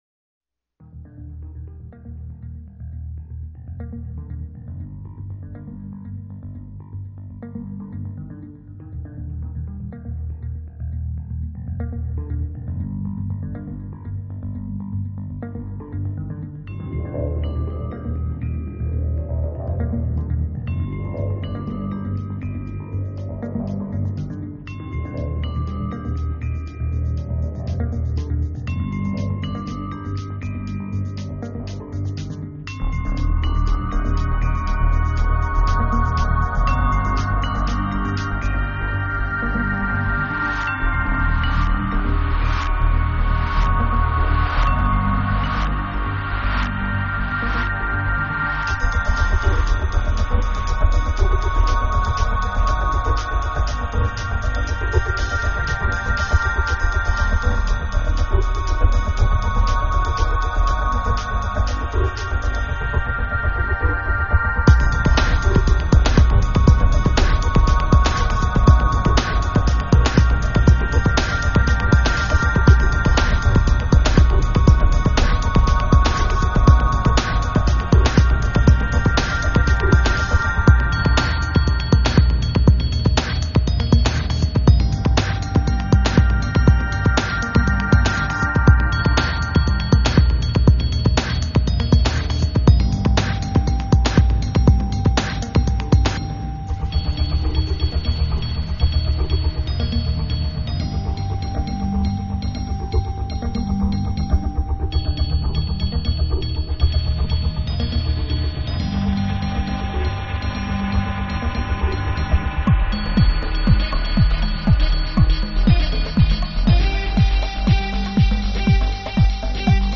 dance, IDM, euro & ambient styles